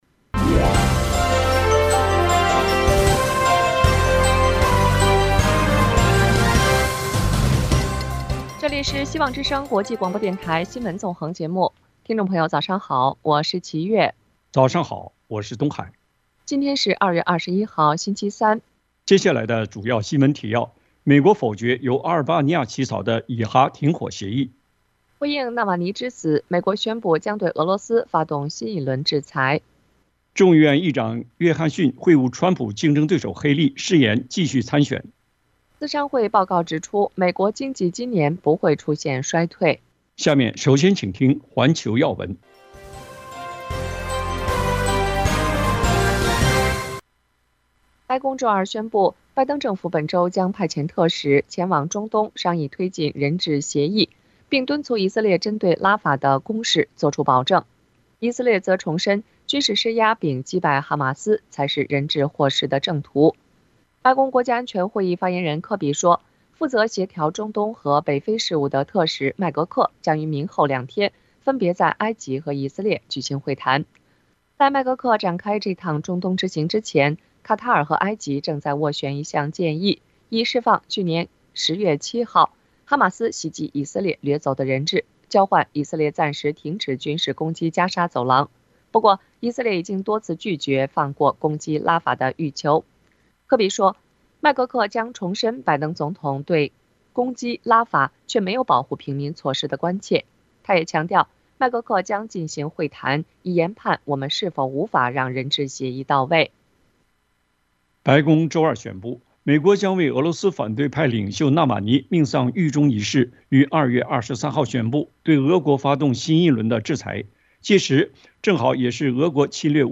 80多个团体联合反对香港23条国安立法 呼吁各国施加制裁【晨间新闻】